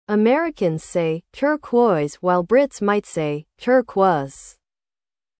Como se pronuncia “turquoise” em inglês?
• 🇺🇸 Inglês americano: /ˈtɜːr.kwɔɪz/ (tur-cóiz)
• 🇬🇧 Inglês britânico: /ˈtɜː.kwɔɪz/ (tã-cóiz ou tã-quóiz)
Exemplos falados no contexto: